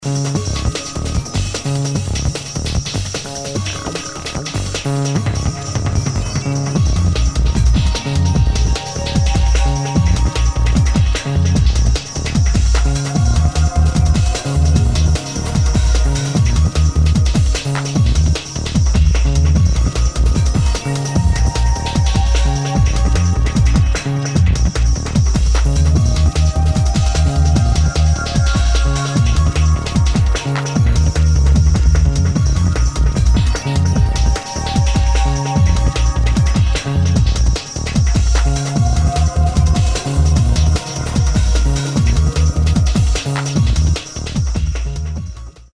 [ TECHNO / ACID / TRANCE ]